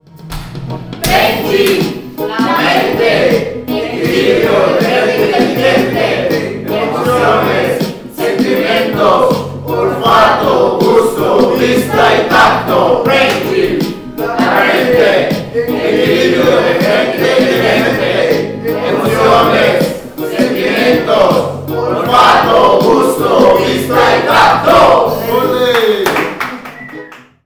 Per acabar, compartirem una part del rap que vam crear amb l’alumnat amb la il·lusió d’aquell dia per fer un bon tancament del projecte!